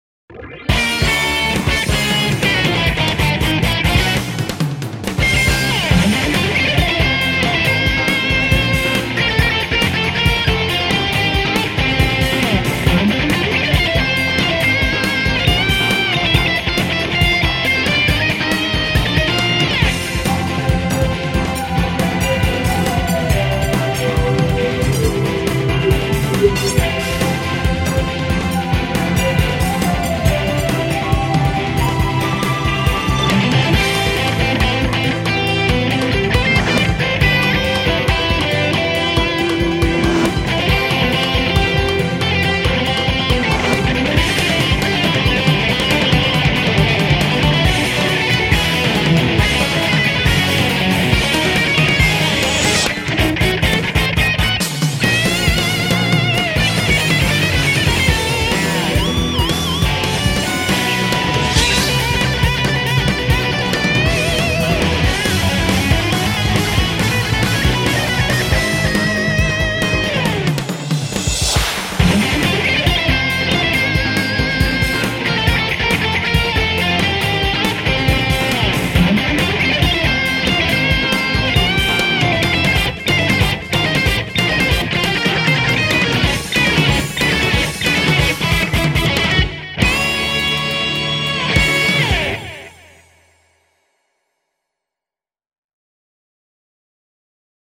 퓨젼곡입니다